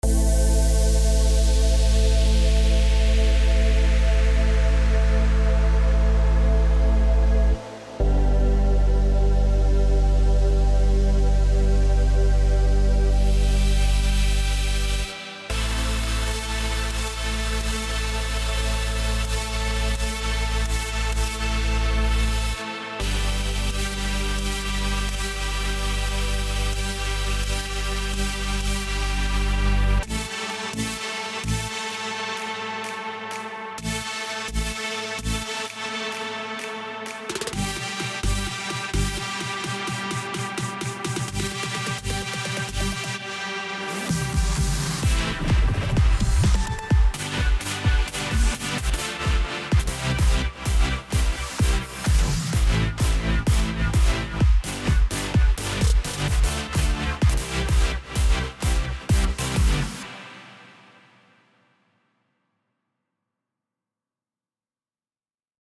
לופ.mp3 משהוא קצר לפני ספירת העומר שעשיתי היום, זה לא מושלם מבחינת מיקס וסאונד, אבל מה אתם אומרים